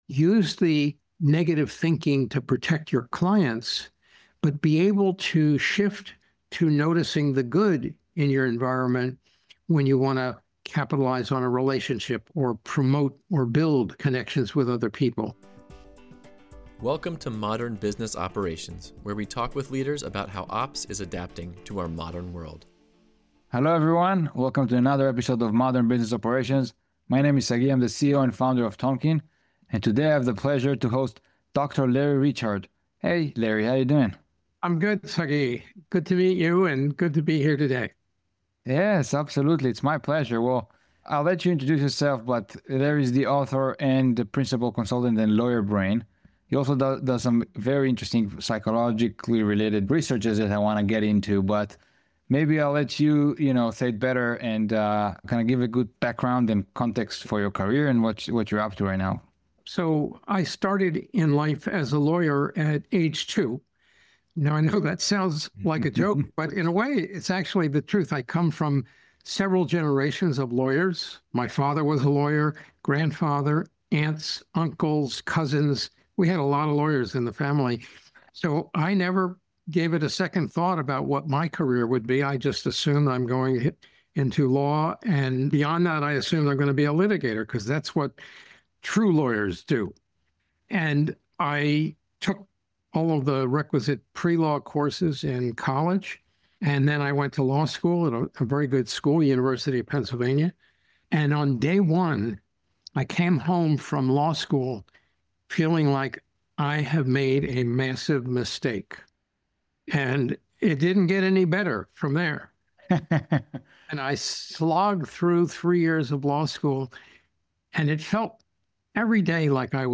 Play Rate Listened List Bookmark Get this podcast via API From The Podcast On Modern Business Operations, we’ll bring you weekly interviews with leaders from some of today’s largest and fastest-growing companies. We discuss the future of operations within business, including finance, revenue, legal, and HR.